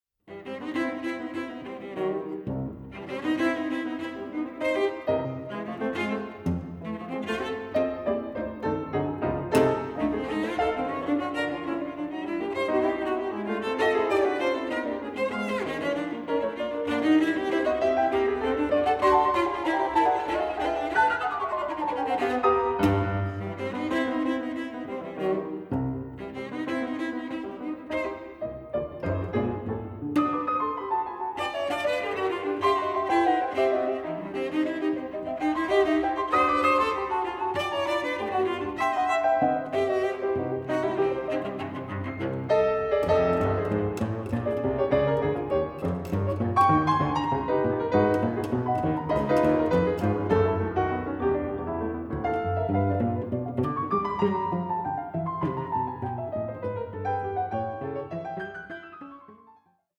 for cello and piano